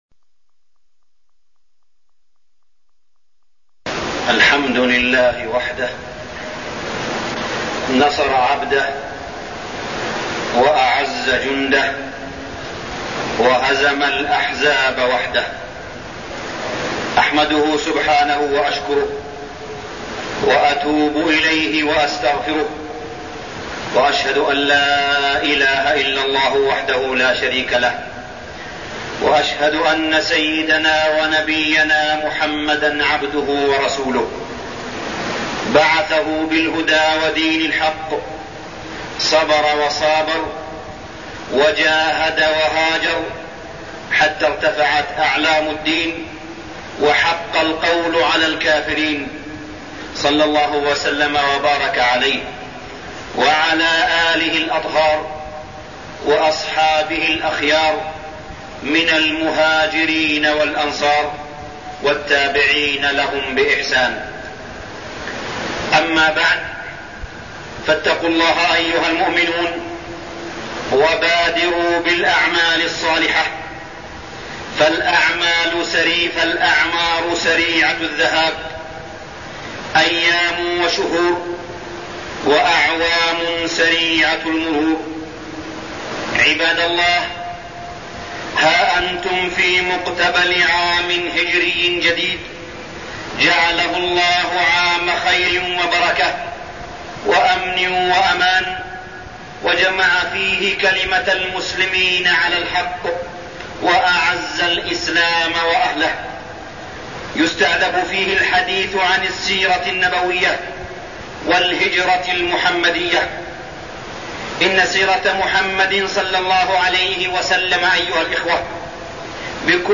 تاريخ النشر ١٩ صفر ١٤٠٩ هـ المكان: المسجد الحرام الشيخ: معالي الشيخ أ.د. صالح بن عبدالله بن حميد معالي الشيخ أ.د. صالح بن عبدالله بن حميد فضائل يوم الجمعة